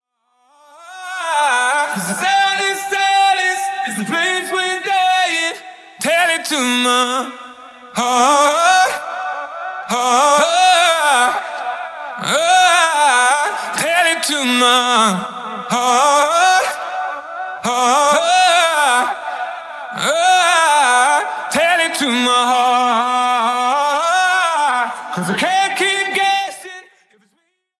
Listen to the demo acapella on our official channel :